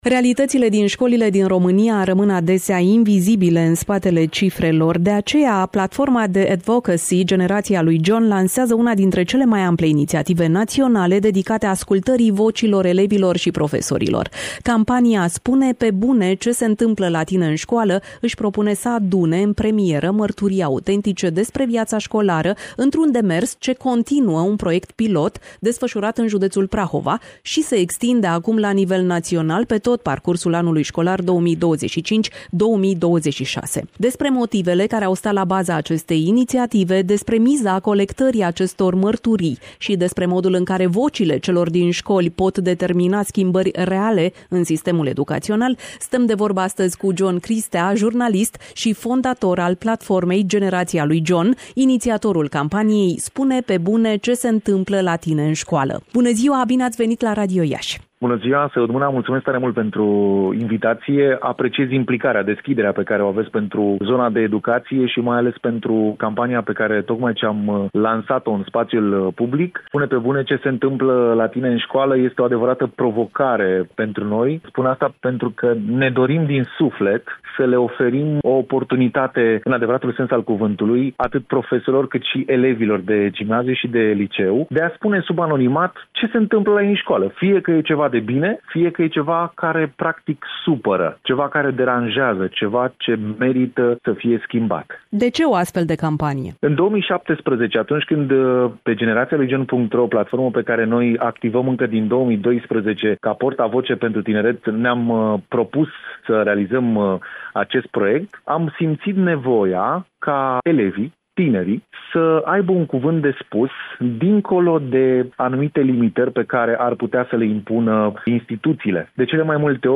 (INTERVIU) A fost lansată campania națională ”Spune pe bune ce se întâmplă la tine în școală.”
Varianta audio a interviului: